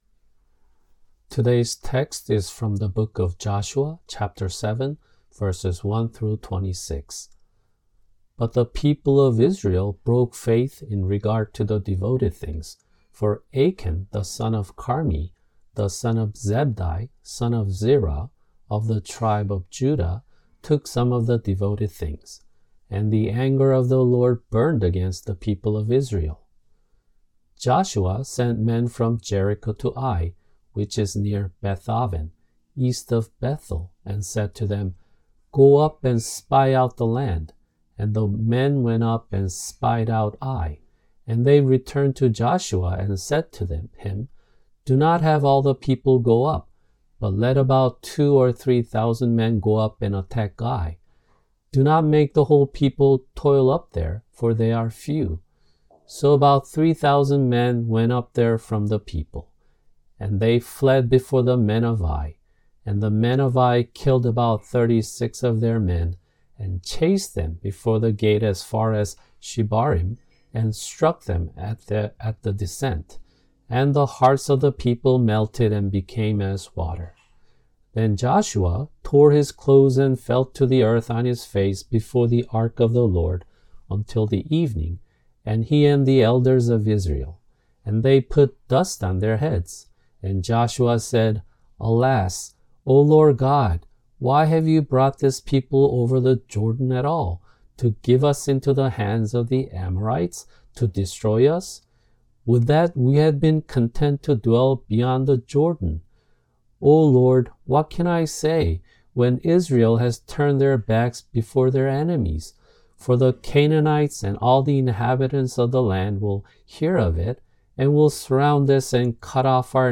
[주일설교] 여호수아 2:1-14